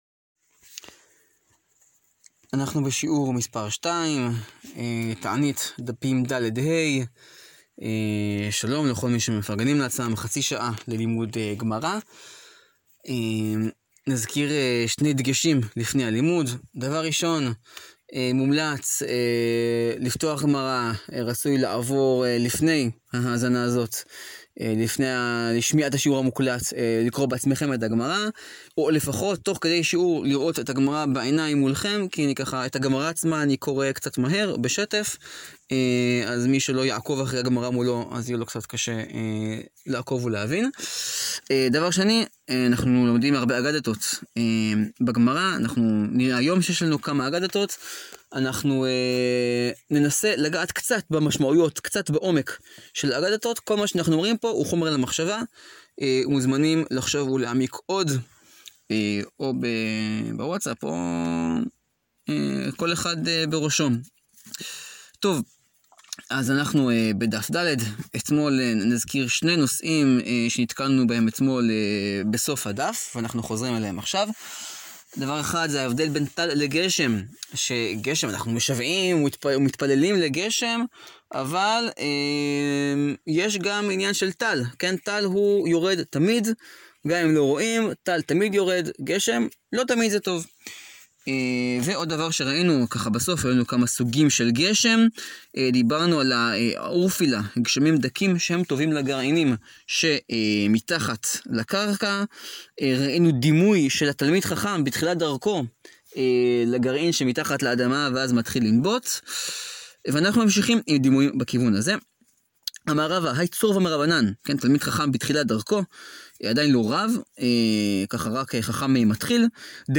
מסכת תענית ללימוד לסיום מסכת, דפים ד-ה, שיעור 2 מתוך 14.